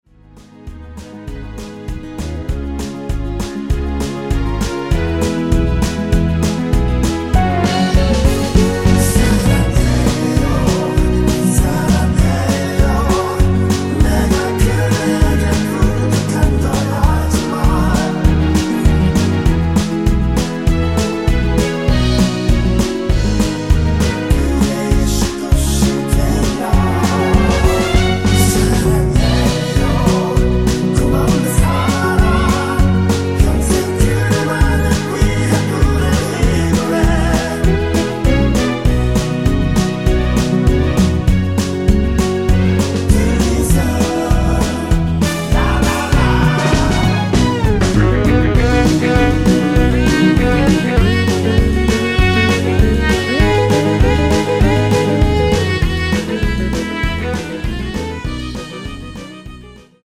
원키에서(-1)내린 코러스포함된 MR입니다.(미리듣기 확인)
◈ 곡명 옆 (-1)은 반음 내림, (+1)은 반음 올림 입니다.
본 사이트에서 처음 mr 구입해보는데요, 음질도 좋고 코러스도 적절하게 잘 들어가있네요.
앞부분30초, 뒷부분30초씩 편집해서 올려 드리고 있습니다.